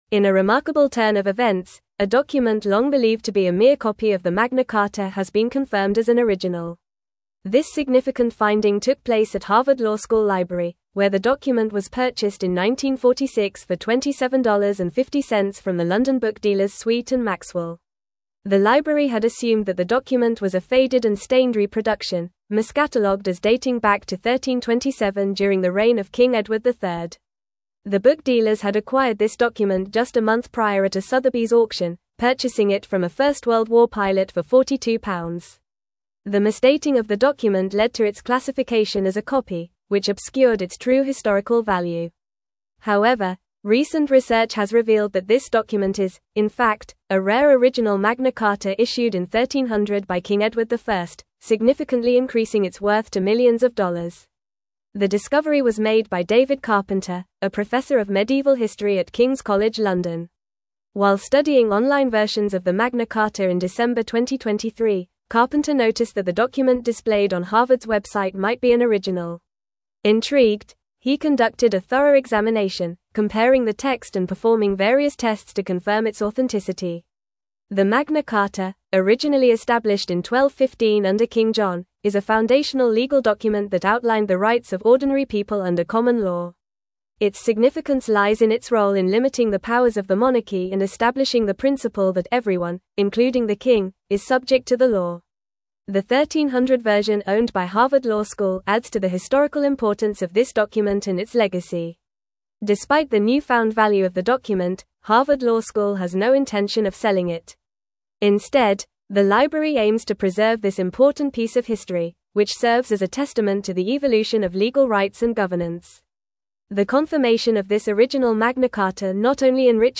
Fast
English-Newsroom-Advanced-FAST-Reading-Harvard-Library-Confirms-Original-Magna-Carta-Discovery.mp3